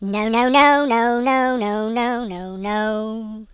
share/hedgewars/Data/Sounds/voices/HillBilly/Nooo.ogg
Nooo.ogg